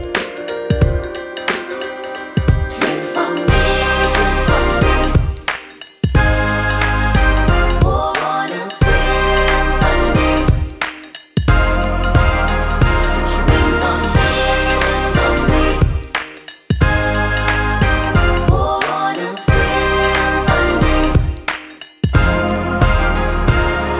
Duet Version Pop (2010s) 3:46 Buy £1.50